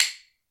拍子木っぽい音１
sounds_Hyoshigi1.mp3